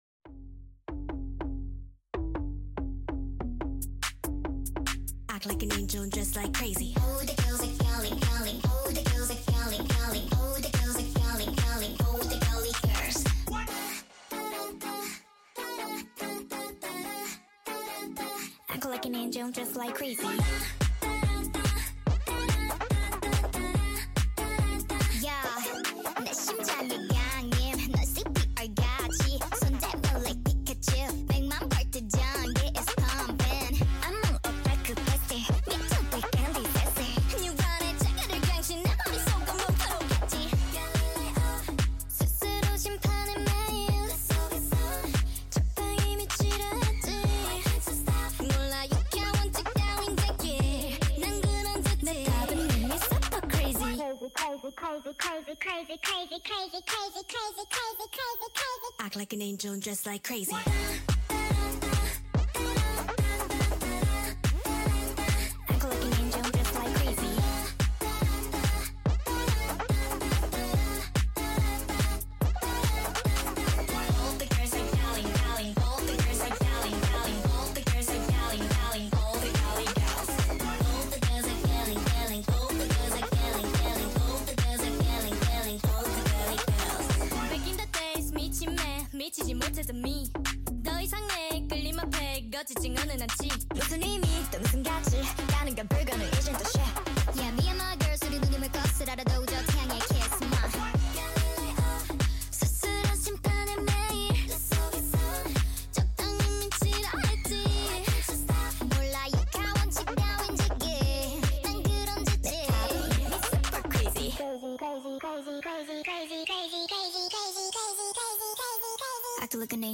Sped Up version